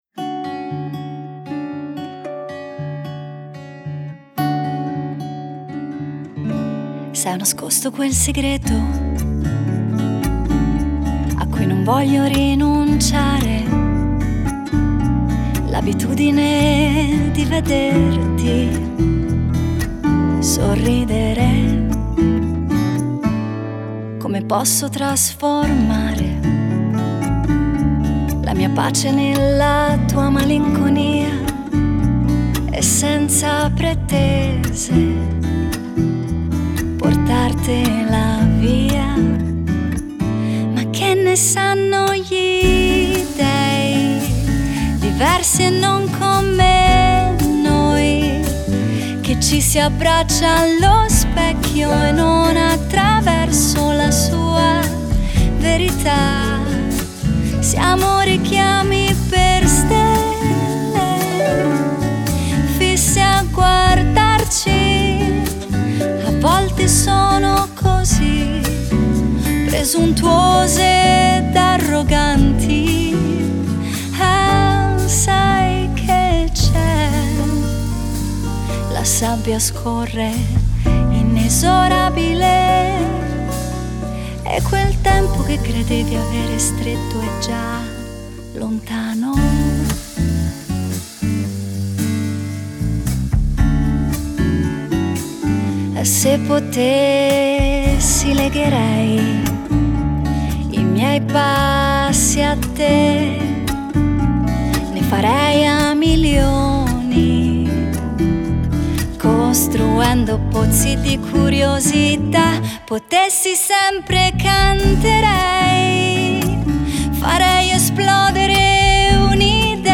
Genere: Pop.